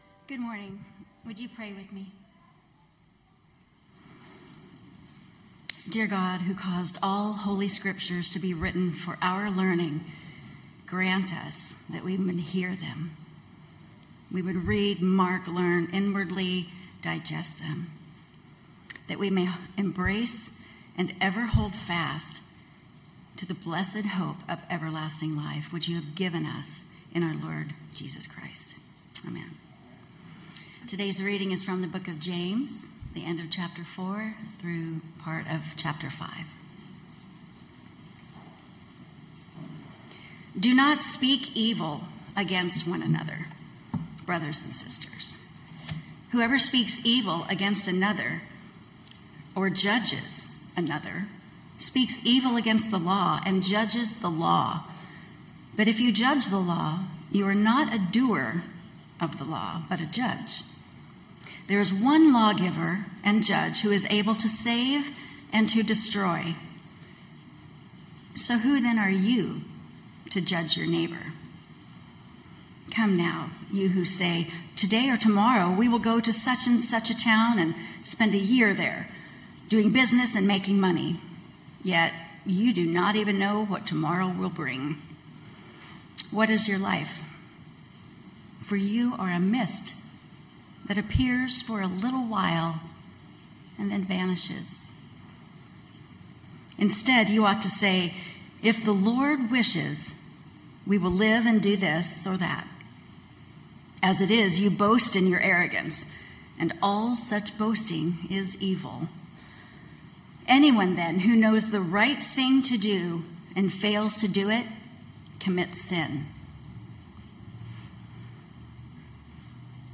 Knox Pasadena Sermons If the Good Lord's Willing Aug 03 2025 | 00:23:31 1x 00:00 / 00:23:31 Subscribe Share Spotify RSS Feed Share Link Embed